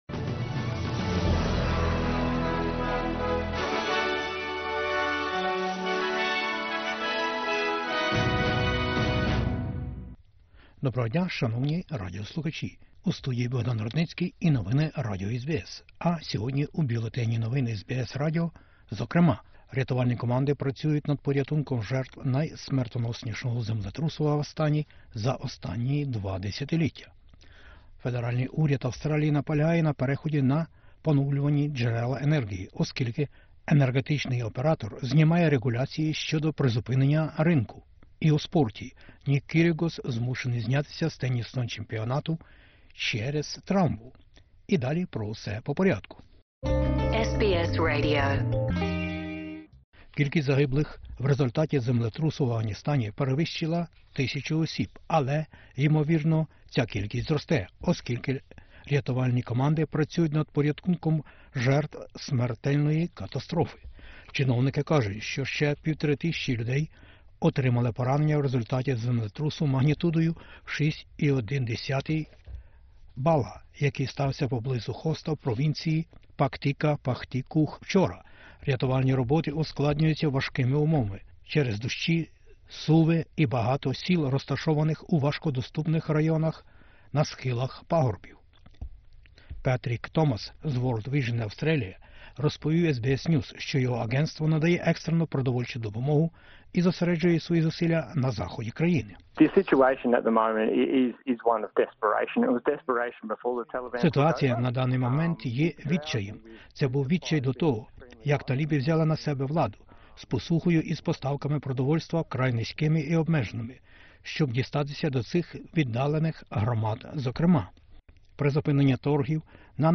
Бюлетень SBS новин українською мовою. Енерґетичної кризи вже не буде, кажуть речники Федерального уряду Австралії. Україна за крок до дорожньої карти до ЄС. Страйк у Новій Південній Валії.